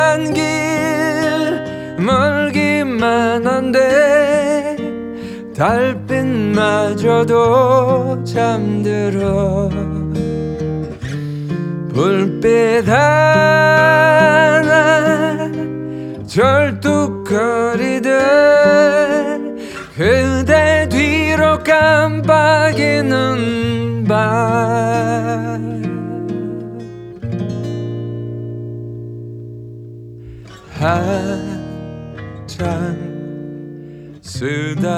Folk Pop K-Pop
Жанр: Поп музыка / Фолк